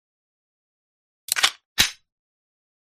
Gunshot
# gun # shot # bang About this sound Gunshot is a free sfx sound effect available for download in MP3 format.
061_gunshot.mp3